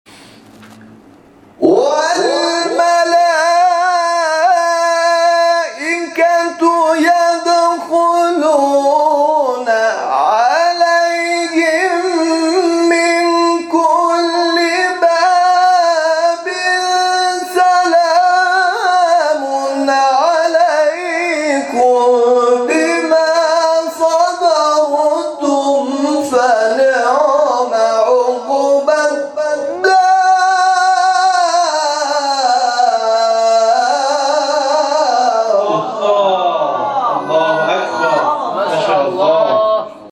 جلسه قرآن محله افسریه
در این فضای شاد و پویا در محله افسریه واقع در جنوب شرقی تهران در مکانی که به نام قمر بنی هاشم(ع) نام گرفته است با این قاریان قرآن همراه شدیم.